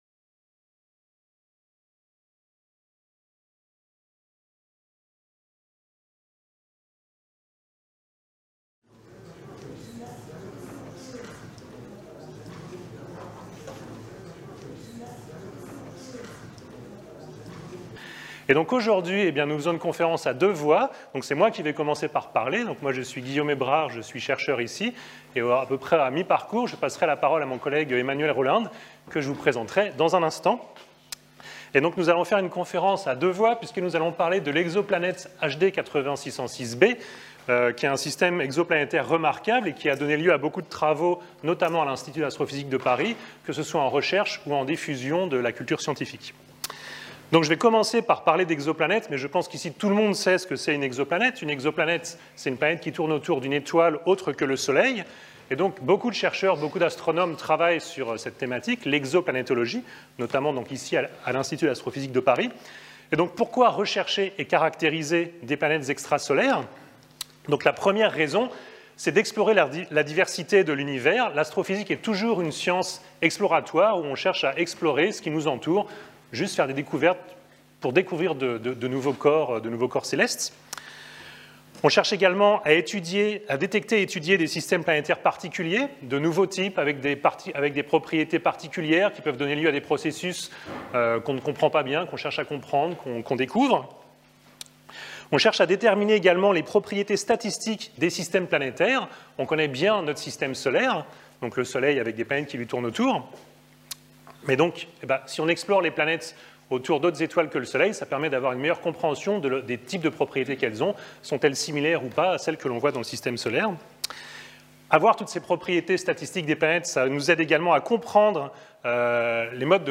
Nous présenterons lors de cette conférence des études menées sur le système HD 80606, dont les caractéristiques sont propices au développement de projets pédagogiques.